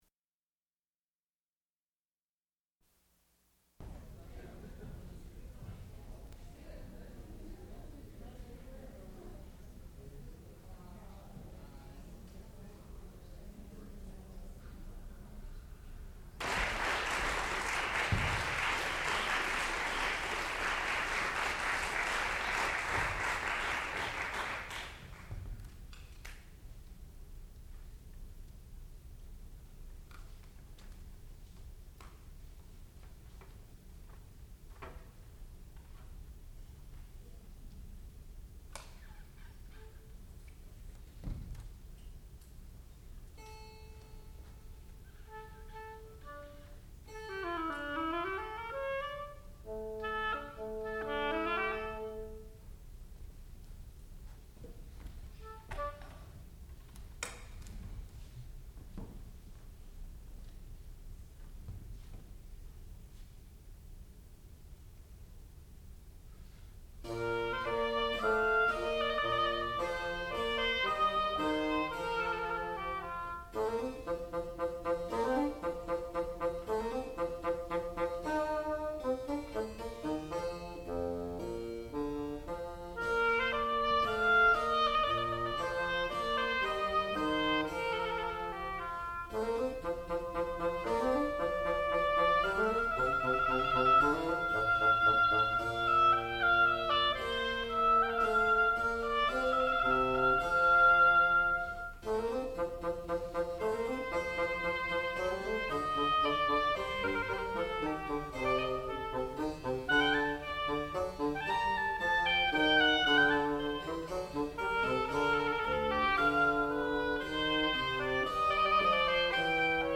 Partita No.1 in B Flat for oboe and continuo
classical music
harpsichord